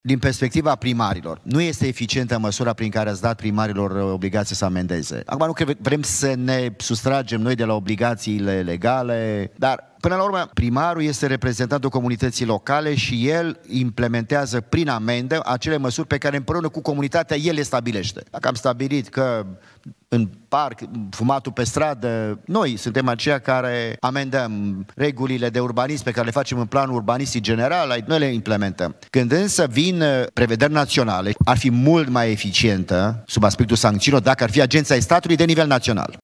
Primarul Clujului, Emil Boc, la o conferință organizată de Uniunea Națională a Societăților de Asigurare și Reasigurare din România și postul de televiziune Antena 3.